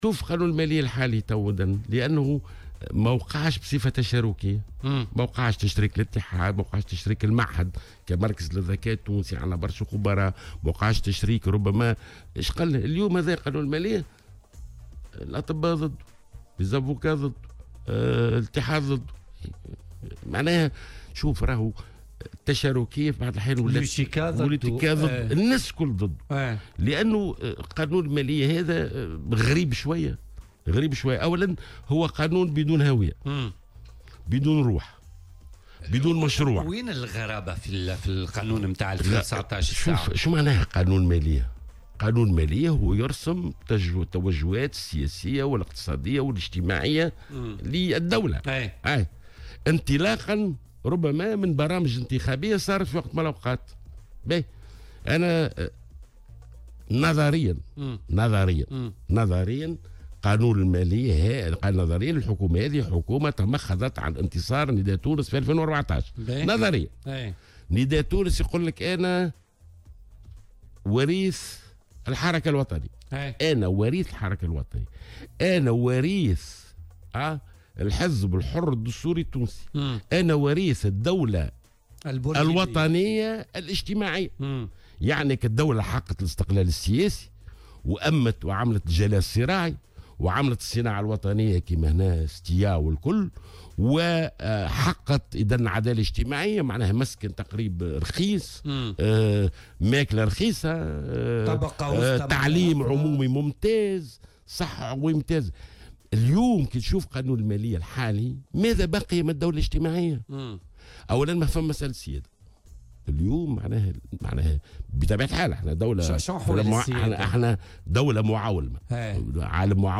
وقال في مداخلة له اليوم في برنامج "بوليتيكا" إنه لم تتم صياغته بصفة تشاركية وإنه قانون "دون هوية وروح"، وفق تعبيره".